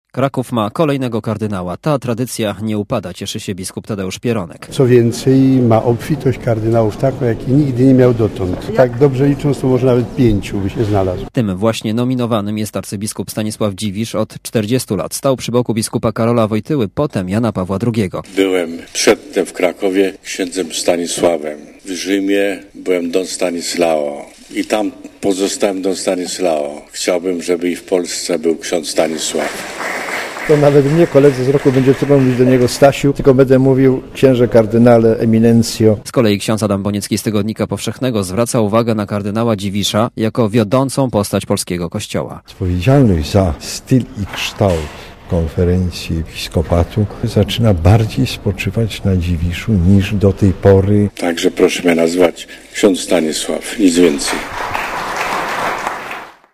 Podczas spotkania ze współpracownikami w kaplicy Pałacu Arcybiskupów